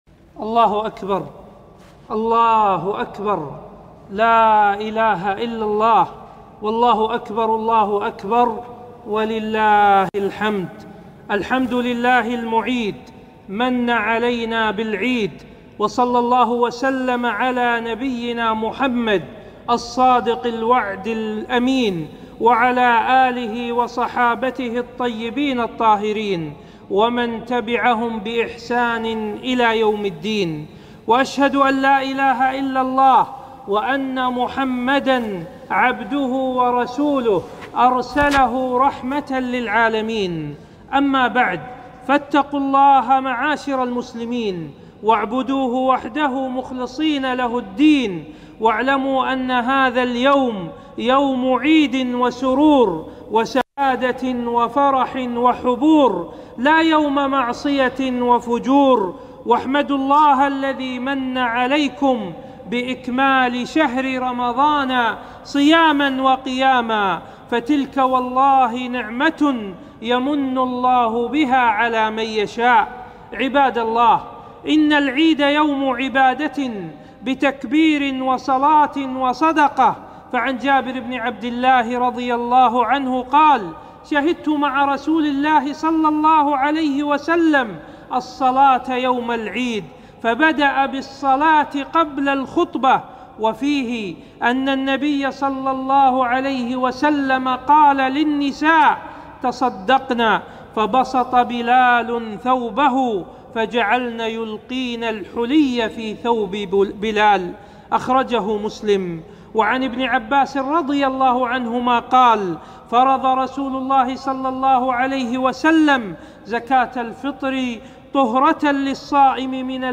خطبة عيد الفطر 1442هــ